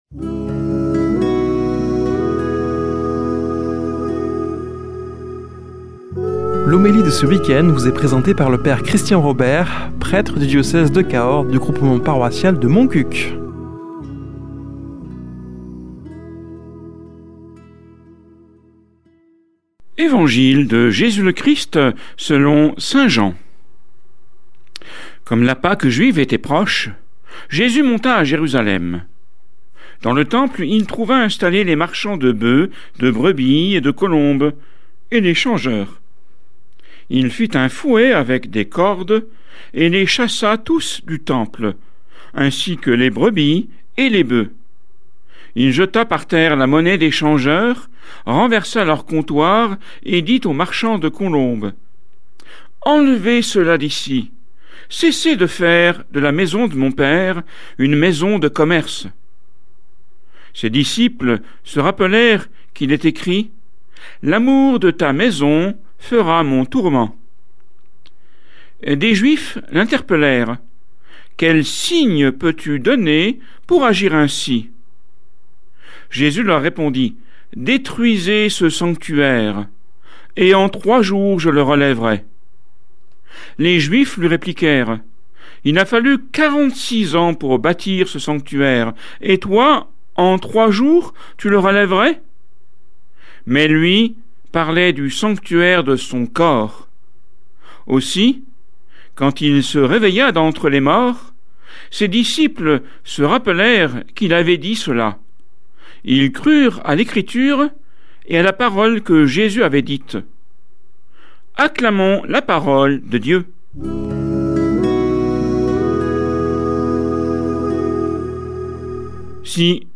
Homélie du 08 nov.